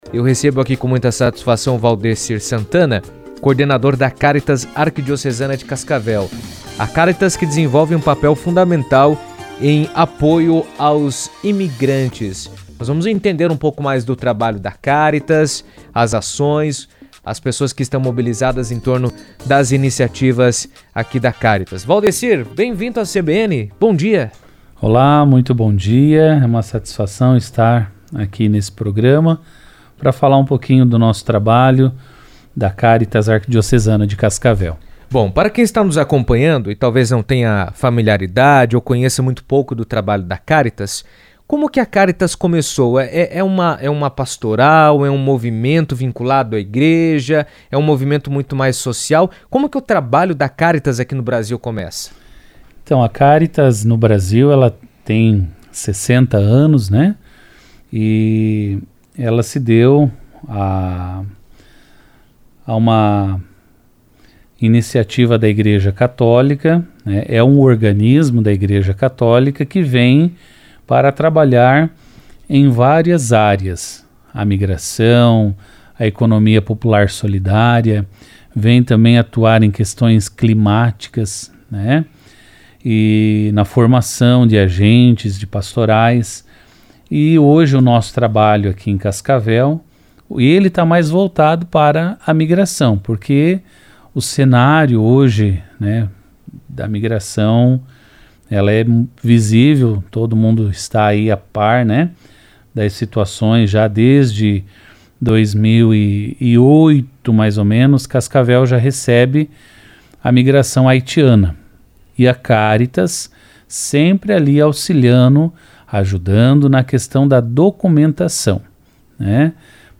A Cáritas Arquidiocesana de Cascavel vem desenvolvendo ações voltadas ao apoio de imigrantes, oferecendo orientação, serviços de acolhimento e integração social na região. Em entrevista à CBN